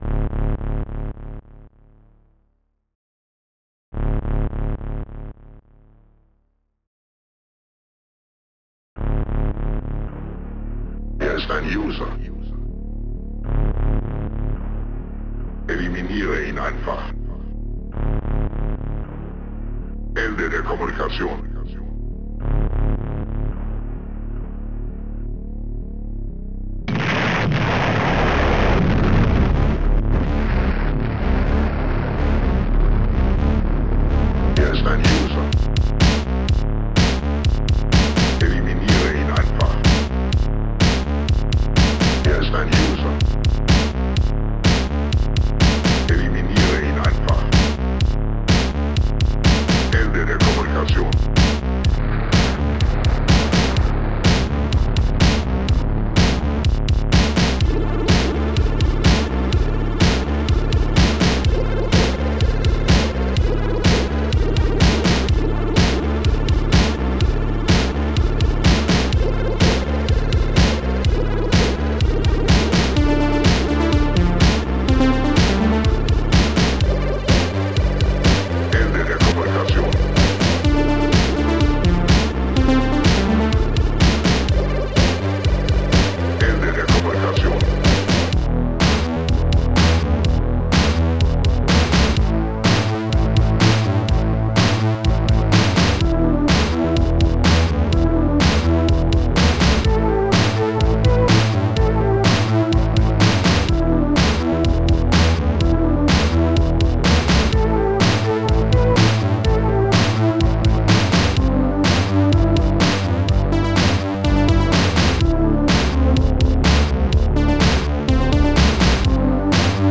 analogstring
strings2